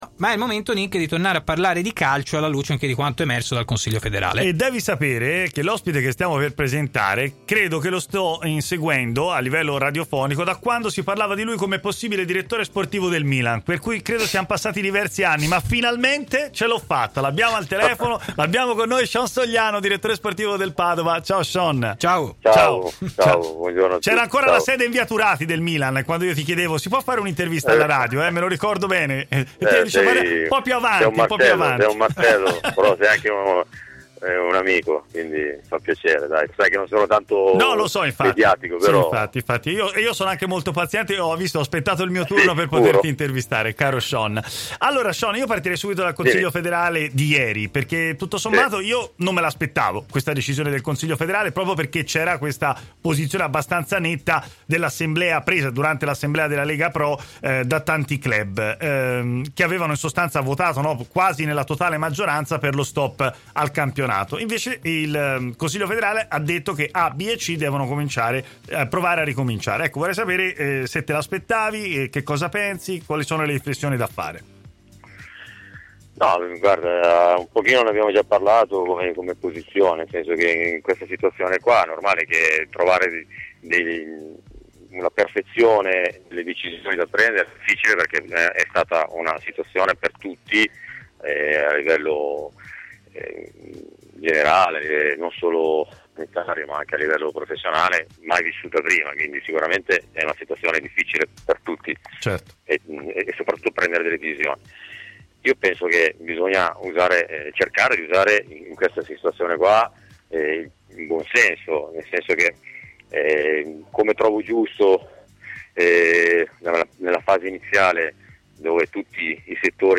è intervenuto in diretta nel corso della trasmissione Stadio Aperto, in onda su TMW Radio